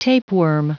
Prononciation du mot : tapeworm